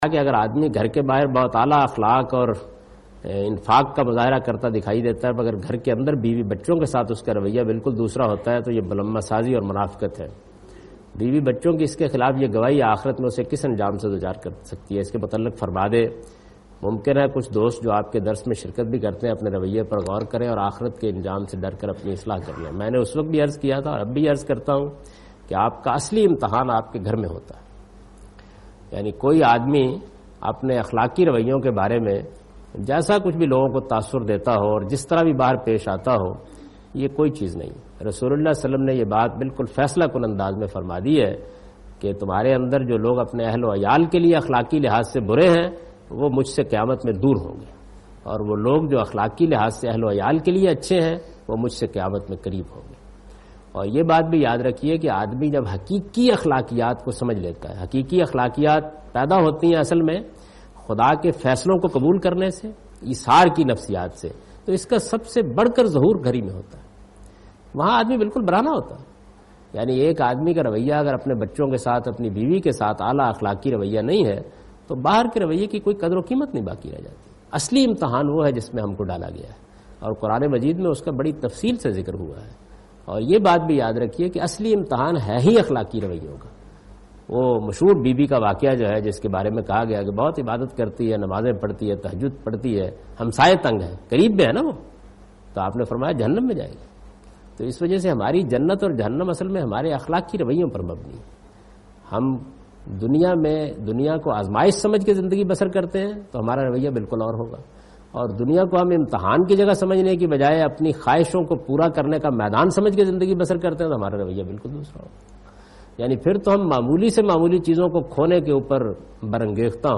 Javed Ahmad Ghamidi responds to the question ' Moral disposition differencees with immediate family and outside family-What's Islamic view on that' ?